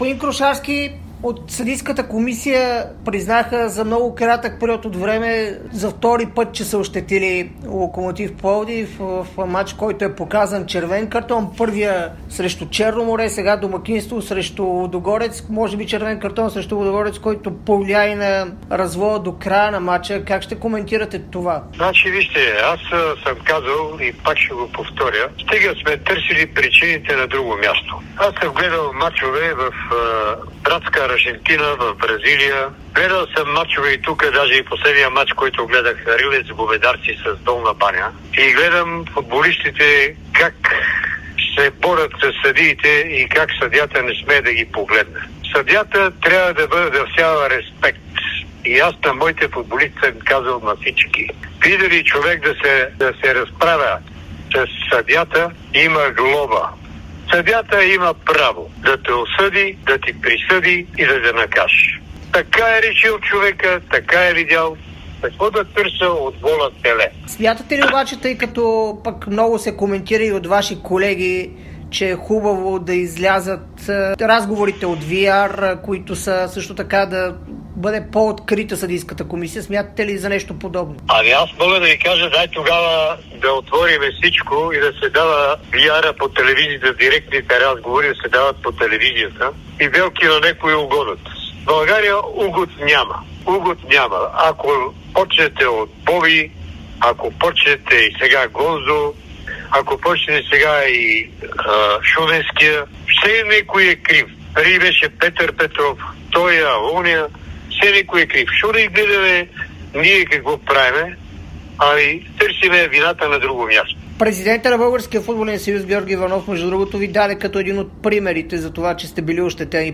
даде интервю за Dsport и Дарик радио.